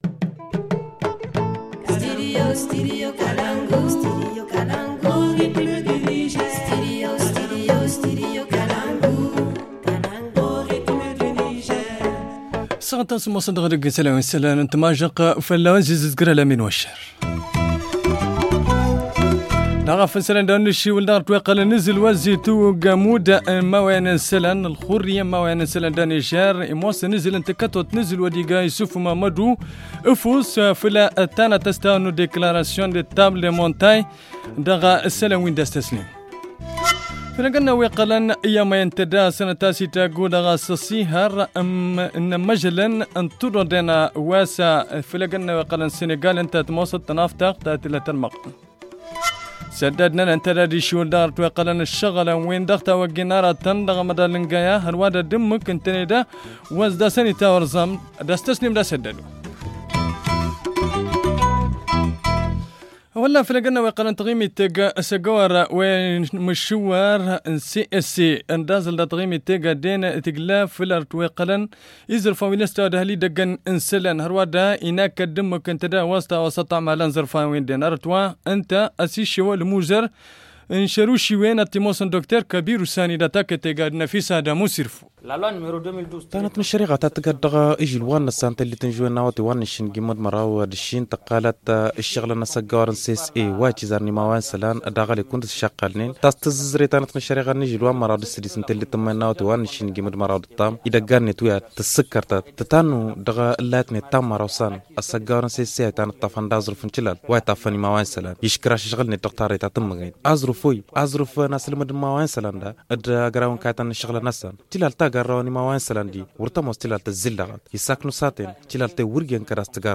Le journal du 29 novembre 2019 - Studio Kalangou - Au rythme du Niger